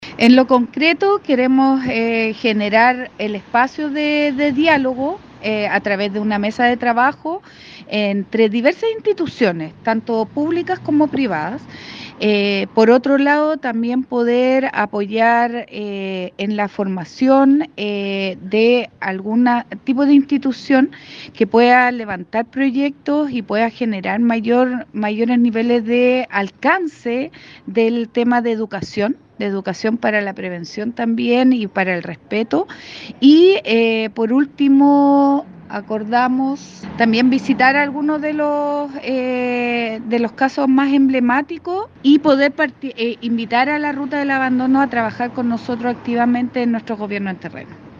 La autoridad provincial indicó que –concretamente- generarán una mesa de trabajo público-privada para abordar el abandono animal; así como establecer instancias de educación sobre la prevención de este problema; y sumar a “La Ruta del Abandono” a las diversas jornadas de Gobierno en Terreno.